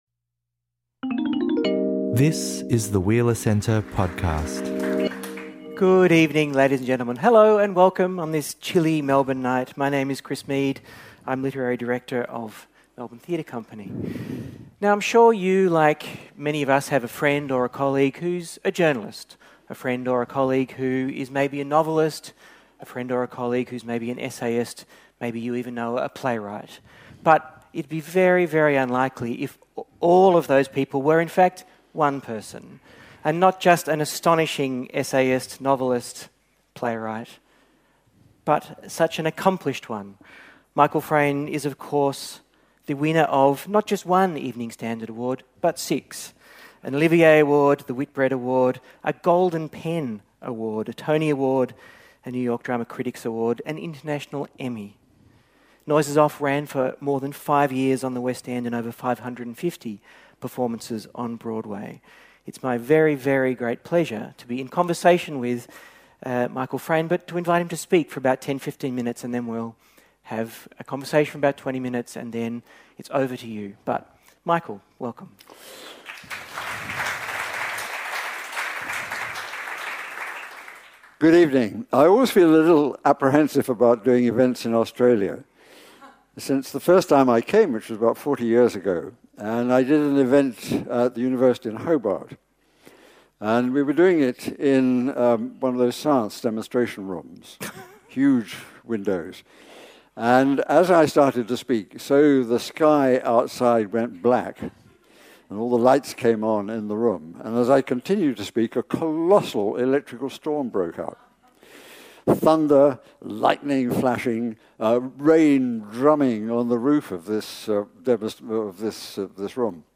Celebrating fifty years since his first novel was published (The Tin Men), and in Melbourne with his wife — noted literary journalist and biographer Claire Tomalin — Michael Frayn comes to the Wheeler Centre to talk about getting started. How do you first begin to write? What happens if you then, years later, take off in a different direction entirely?